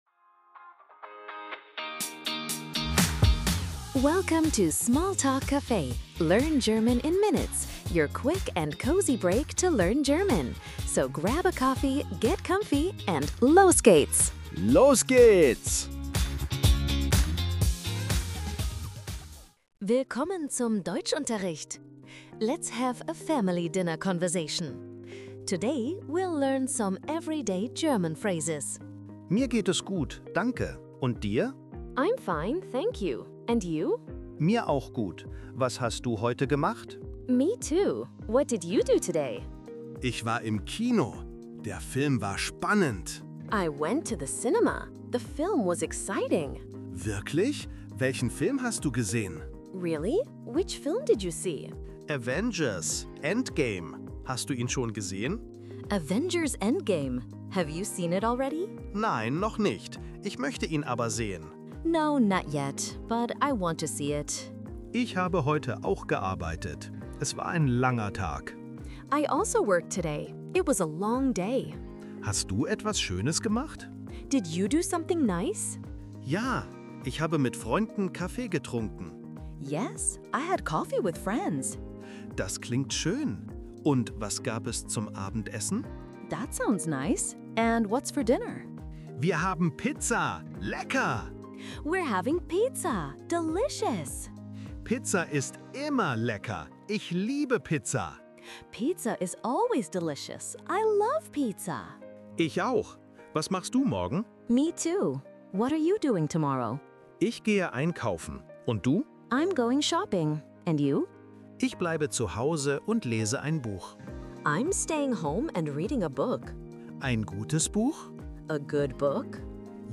Family dinner, German conversation practice! Lernen Sie Deutsch mit Leichtigkeit.
Tune in for quick, real-life dialogues, helpful tips, and the confidence boost you need to navigate daily errands in German!